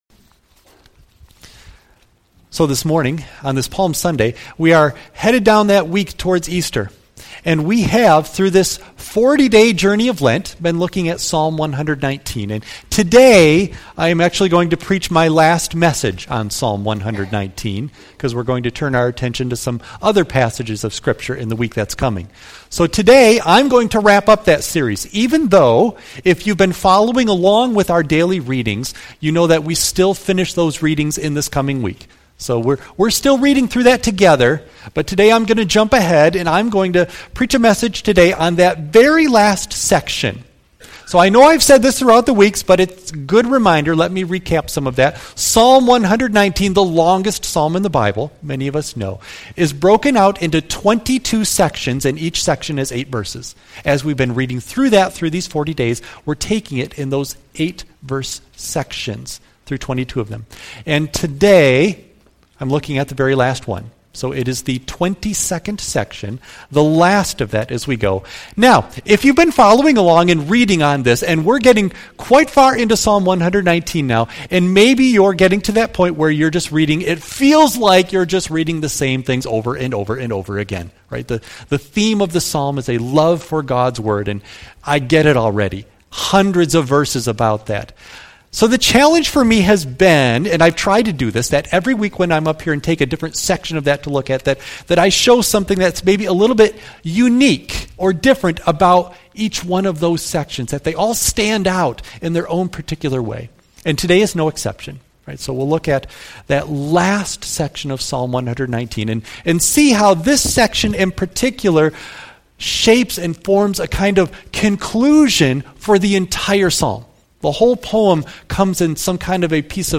Service Type: Sunday AM